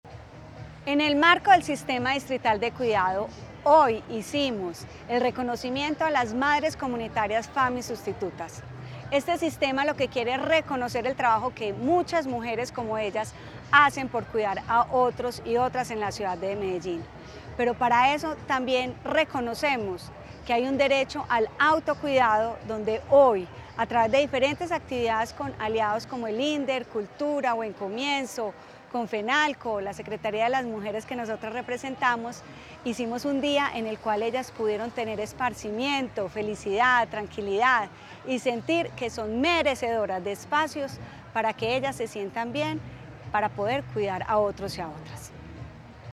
Palabras de Valeria Molina, secretaria de las Mujeres